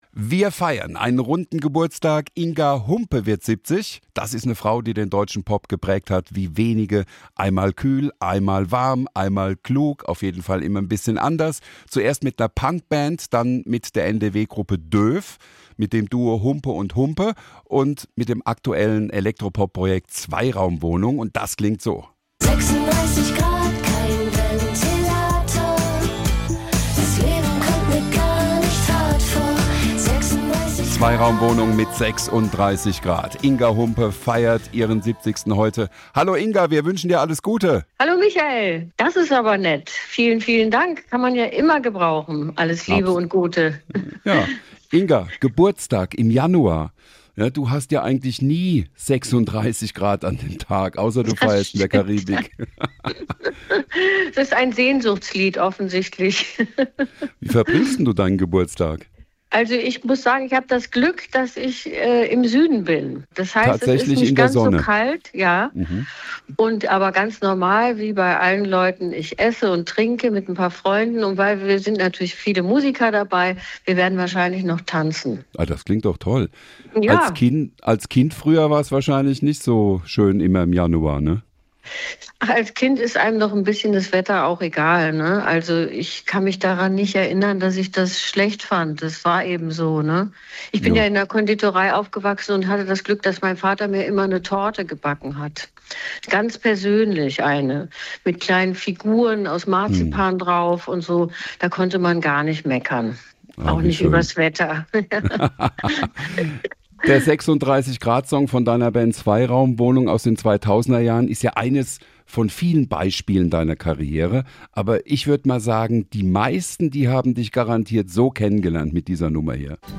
Mit dem Erfolg hatte Sängerin Inga Humpe damals nicht gerechnet, erzählt sie im SWR1 Interview.